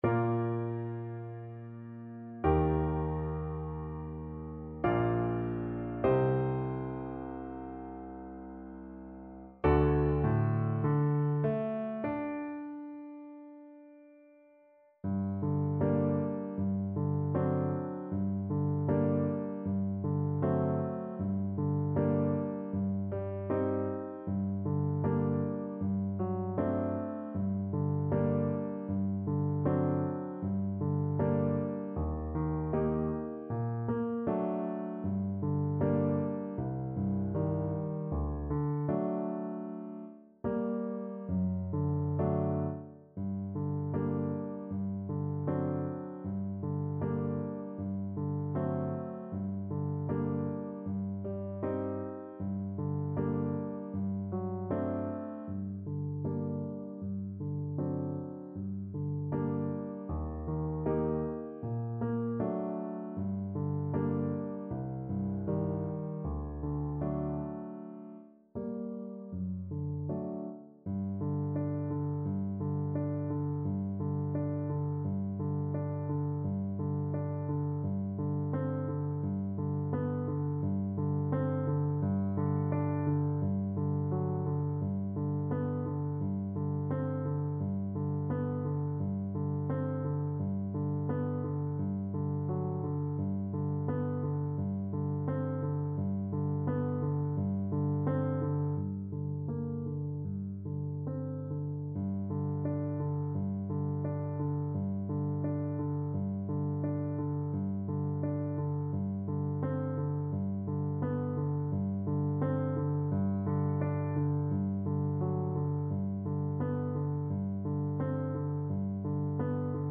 Play (or use space bar on your keyboard) Pause Music Playalong - Piano Accompaniment Playalong Band Accompaniment not yet available transpose reset tempo print settings full screen
Bb major (Sounding Pitch) (View more Bb major Music for Violin )
4/4 (View more 4/4 Music)
Largo
Classical (View more Classical Violin Music)